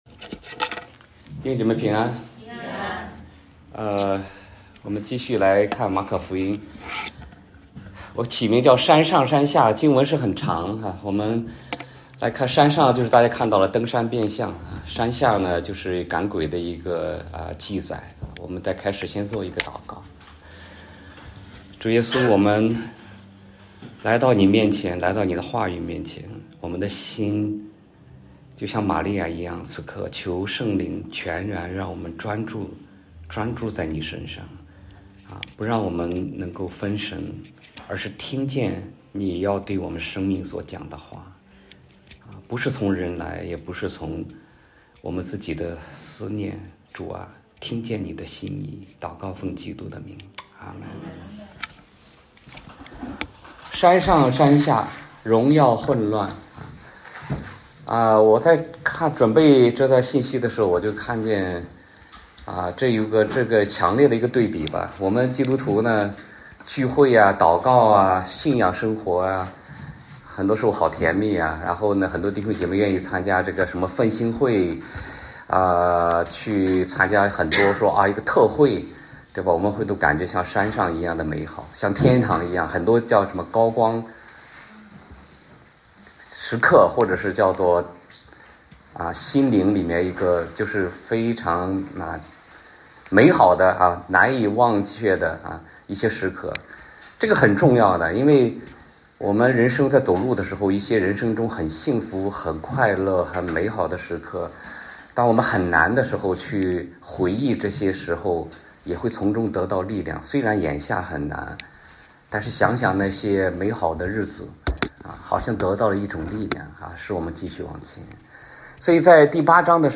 中文主日讲道录音 | University Chapel
Sermon Notes